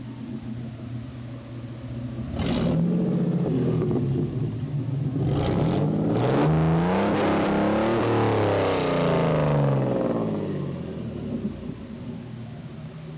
All sounds were taken from a 8mm video camera, and lined-in to the computer.. file sizes are bigger than they should be, but count that for novice user fault.  Video was 8feet away from rear corner, and the truck was cold..
Still waiting on the drive-by sounds, and the 8mm video camera doesn't capture bass that well, so I learned today...
Edelbrock shorty headers, into high flow cat, through to KC performance muffler, then to y-pipe and to dual exhaust with expensive oval tips!
muffled.wav